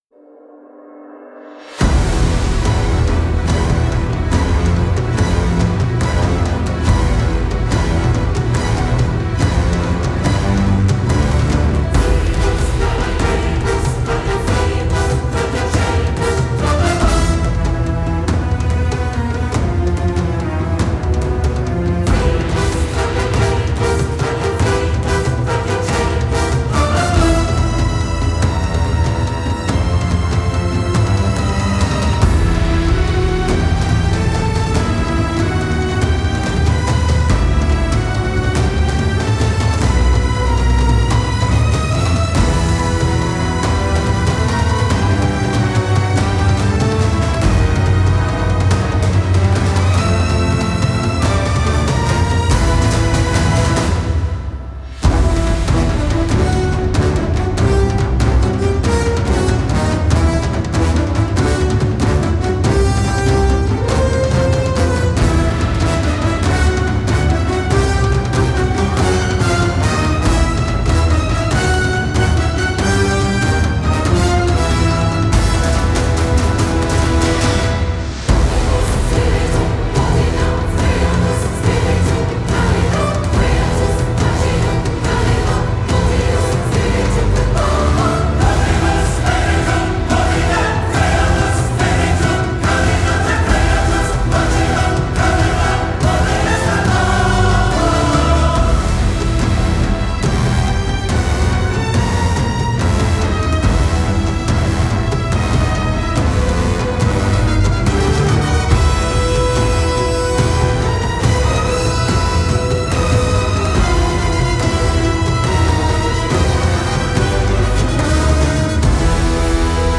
orchestral album recorded at Abbey Road Studios, London.